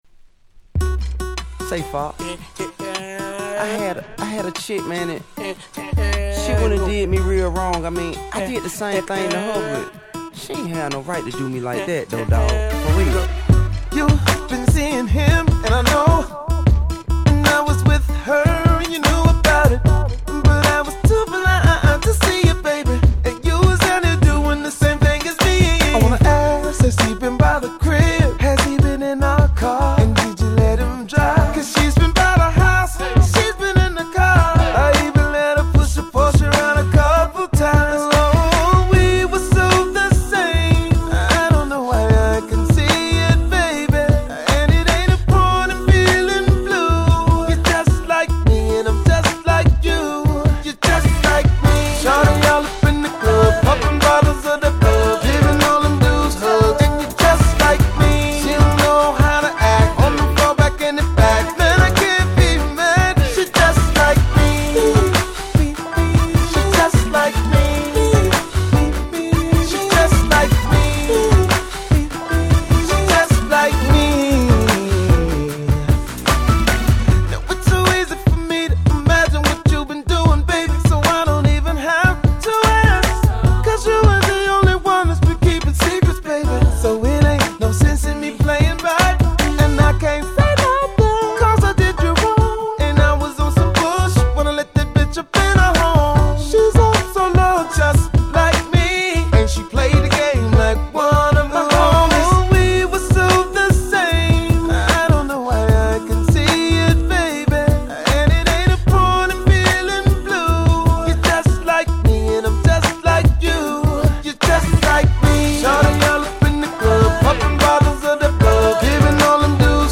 08' Smash Hit R&B !!